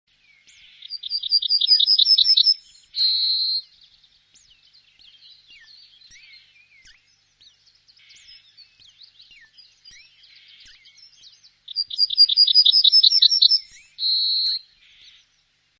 Goldammer
Volksmund und romantisch veranlagte Ornithologen wollen darin ein ,,Wie, wie, wie hab ich Dich liiiiieeeeb!" erkennen. zurück zur Übersicht >
goldammer.mp3